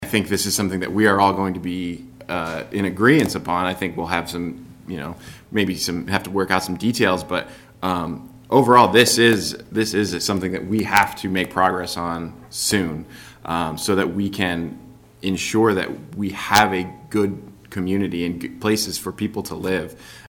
Commissioner Peter Oppelt voiced his support for further discussions and more solutions on the issue.